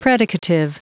Prononciation du mot predicative en anglais (fichier audio)
Prononciation du mot : predicative